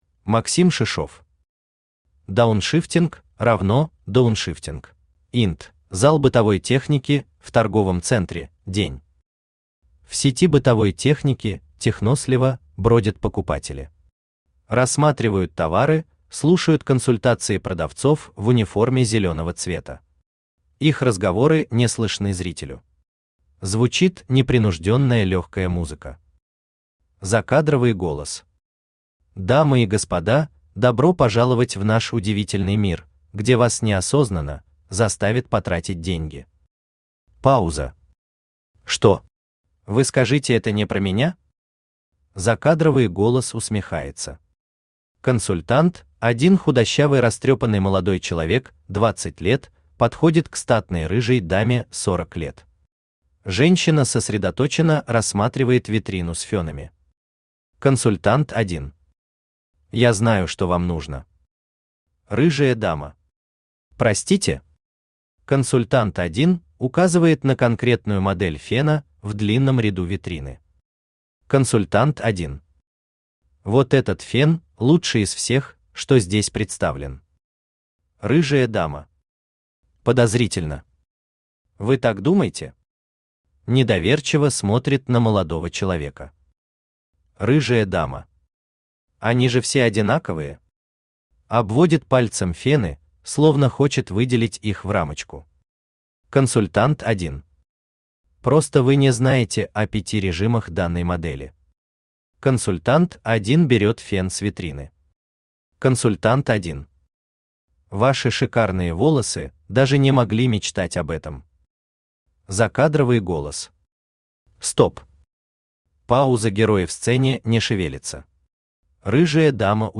Аудиокнига Дауншифтинг = Downshifting | Библиотека аудиокниг
Aудиокнига Дауншифтинг = Downshifting Автор Максим Шишов Читает аудиокнигу Авточтец ЛитРес.